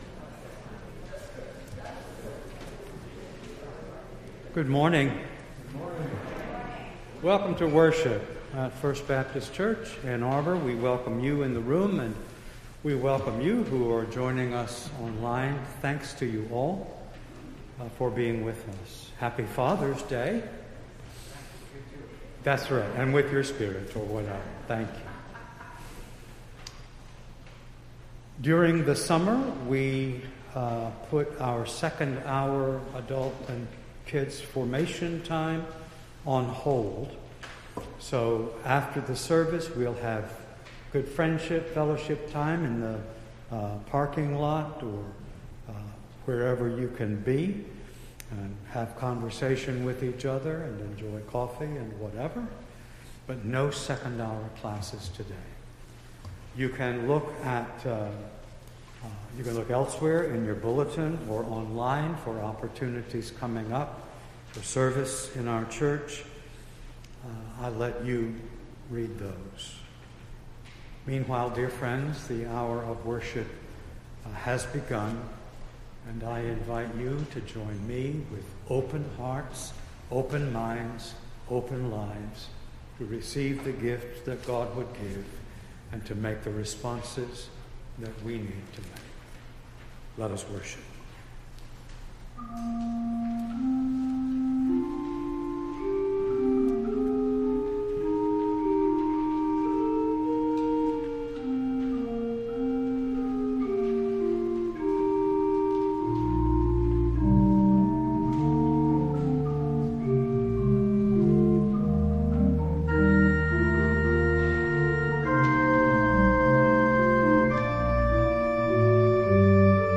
Entire June 19th Service